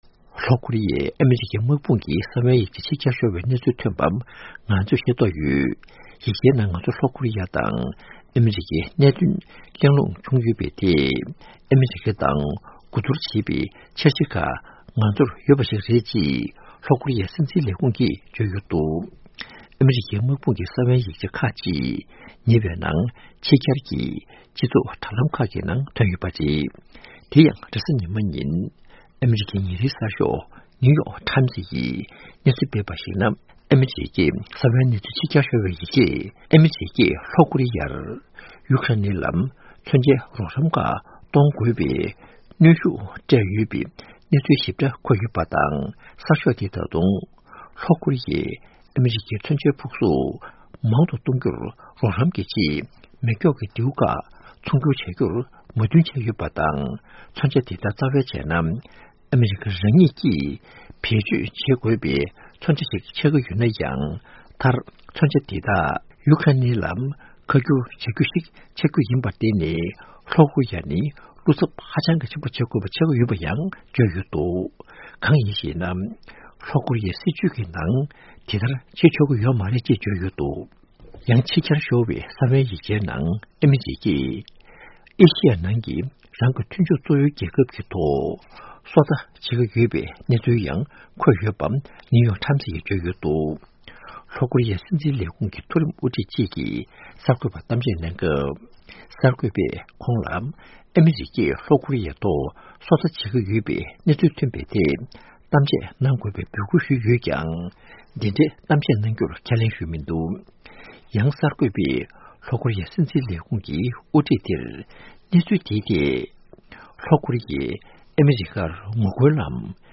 ཕབ་བསྒྱུར་དང་སྙན་སྒྲོན་ཞུས་གནང་བ་འདིར་གསལ།